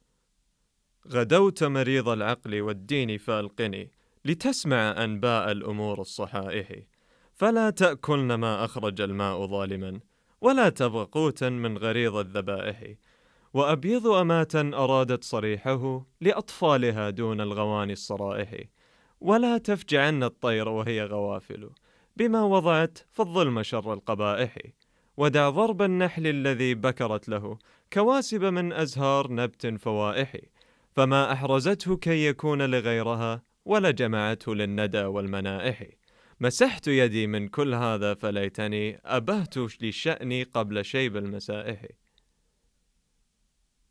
소말리어(쿠시어파)의 음성 샘플